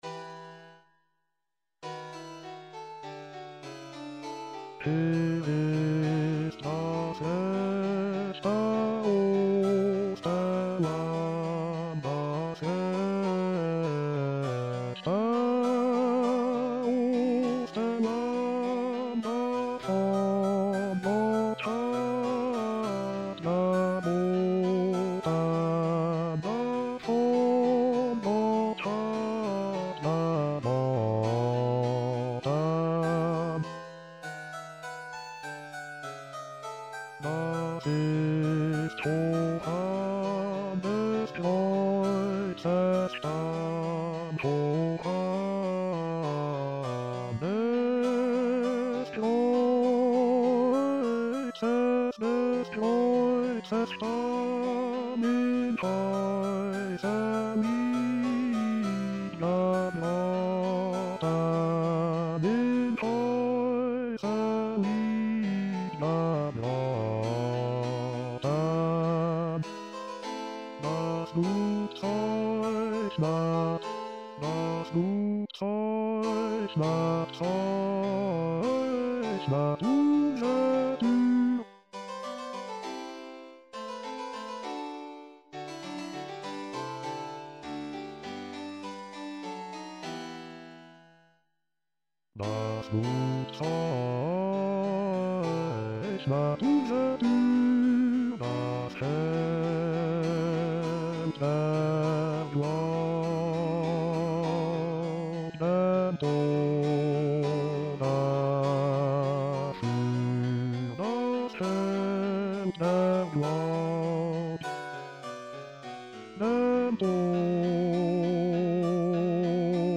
Ce verset uniquement pour les basses.
Ce n'est pas aussi mélodieux que son pendant féminin mais c'est mieux que rien.
Les quelques passages signalés pont été remontés d'une octave.
Basse
versus5_Basse.mp3